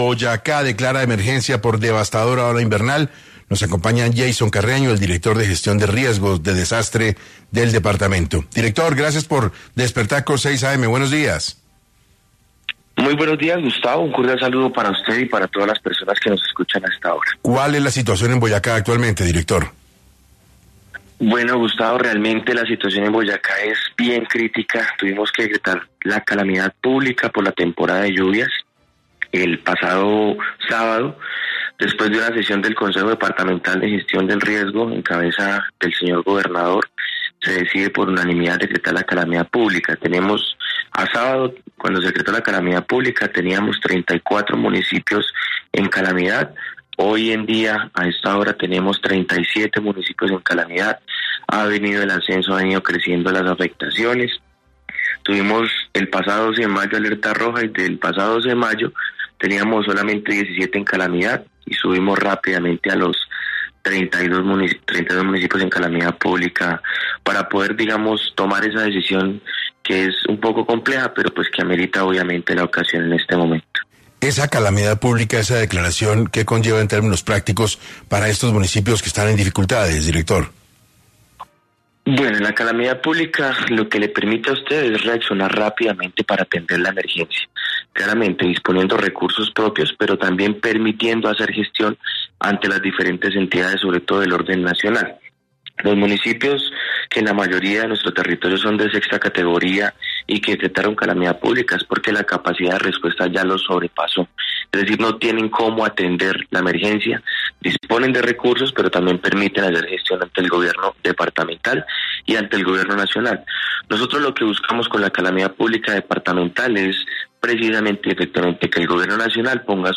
En entrevista para 6AM, el director de la entidad, Jaisson Carreño, amplió la información sobre el estado actual de Boyacá.